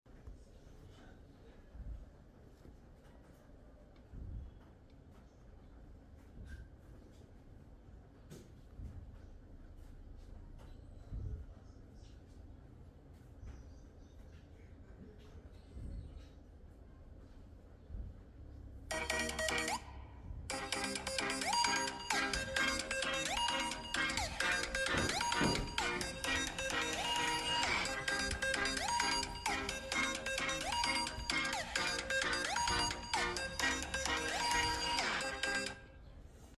Sheriff victory sound sound effects free download